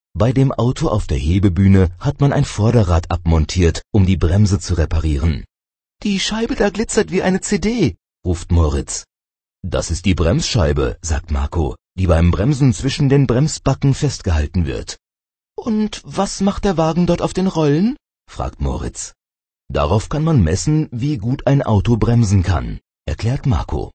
Hörbuch Seite 6